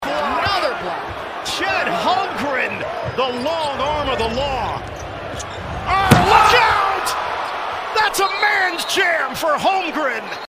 Thunder PBP 10-25.mp3